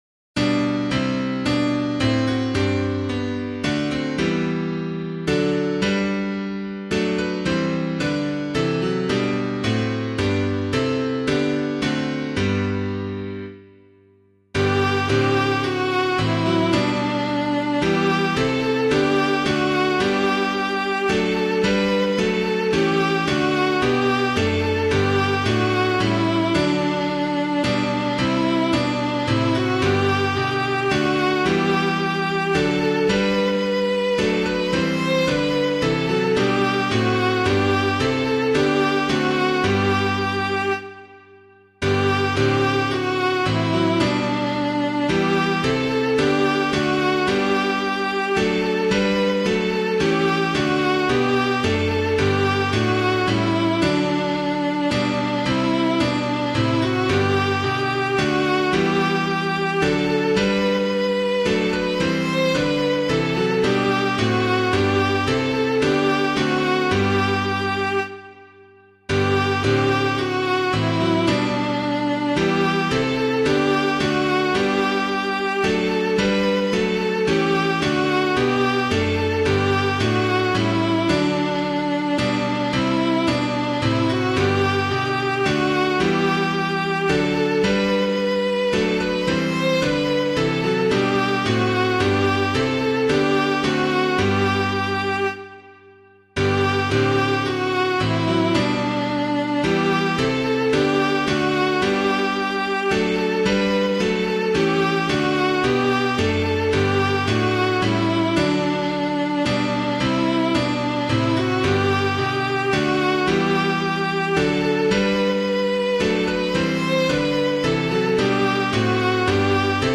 piano
O Jesus Joy of Loving Hearts [Palmer - WAREHAM] - piano.mp3